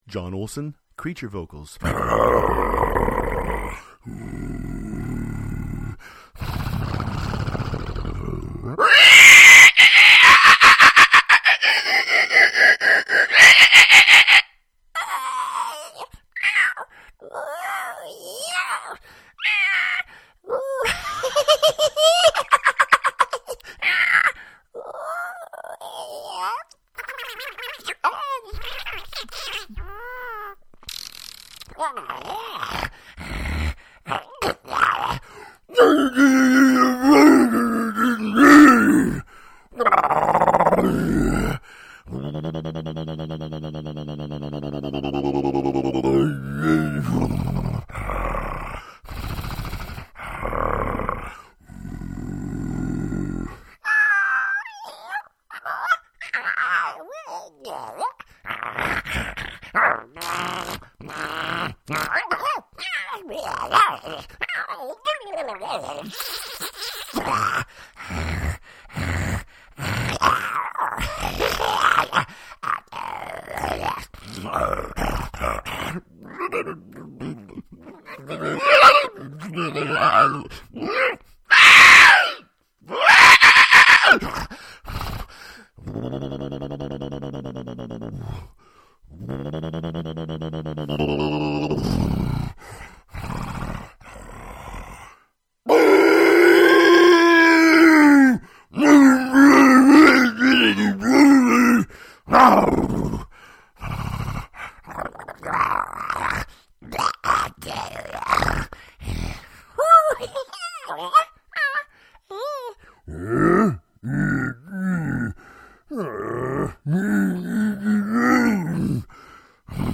VO / Creature
Creature Demo
More Creature Noises